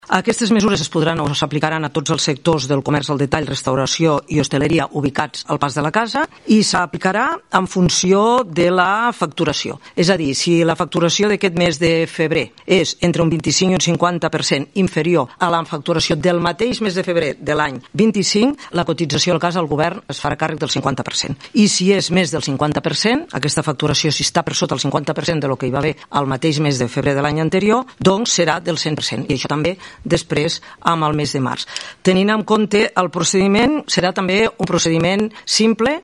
A més, la ministra Conxita Marsol ha anunciat ajuts directes per a les cotitzacions empresa de la CASS.